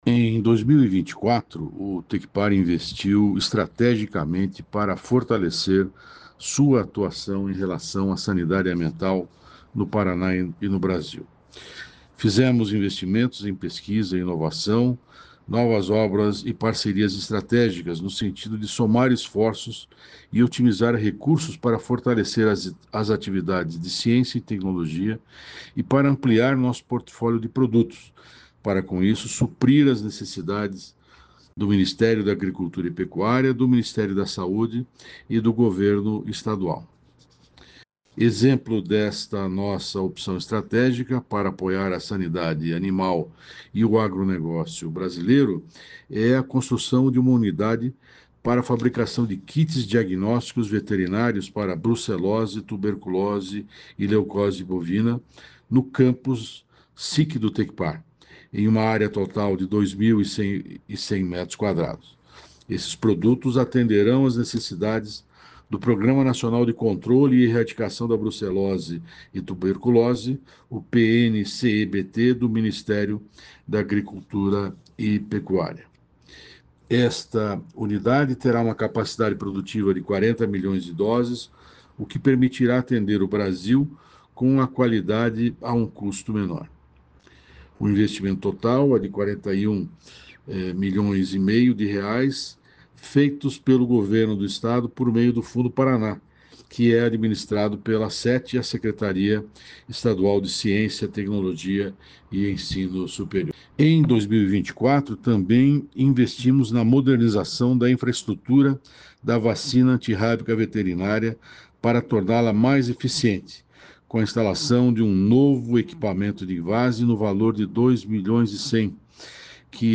Sonora do diretor-presidente do Tecpar, Celso Kloss, sobre o fortalecimento da presença do Instituto na área de sanidade animal